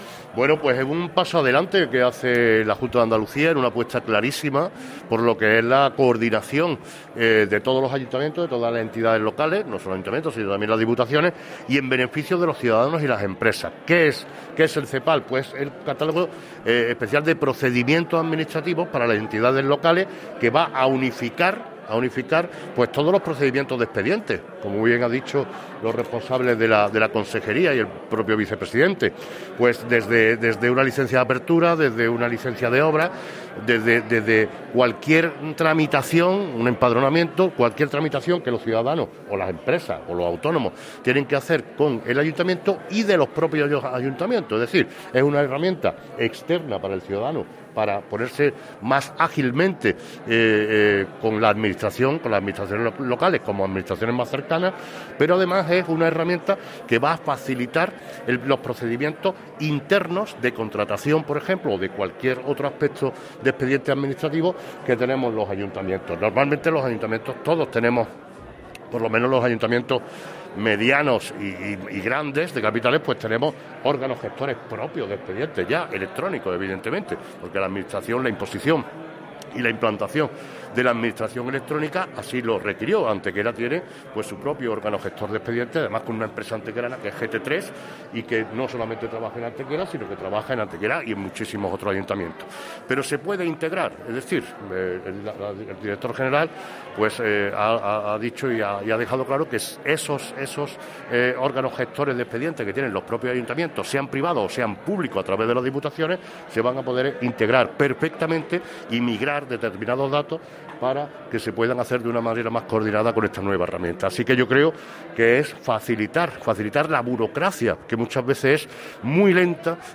El Alcalde de Antequera participa en la jornada de presentación del Catálogo Electrónico de Procedimientos de la Administración Local impulsado por la Junta de Andalucía
Cortes de voz